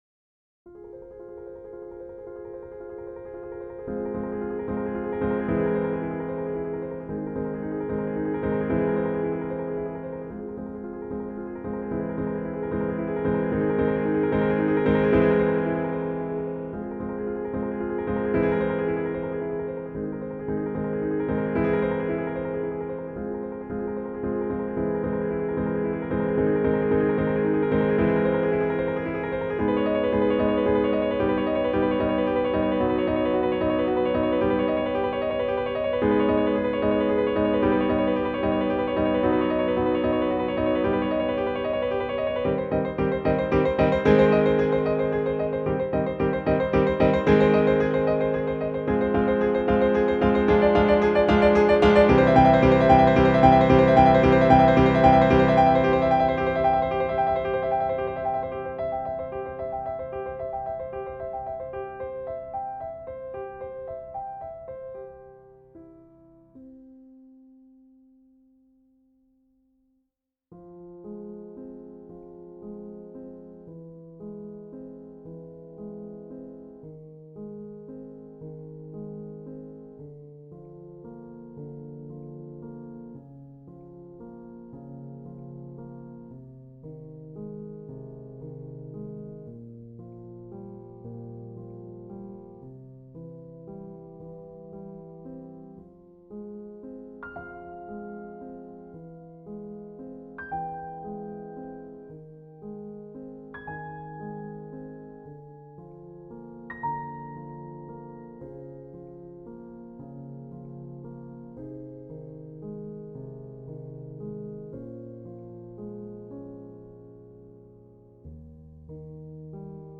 1   Backing Track (minus choir)